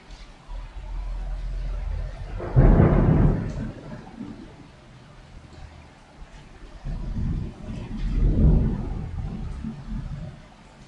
描述：雷，风暴，雨，风，野外录音
Tag: 现场录音 暴风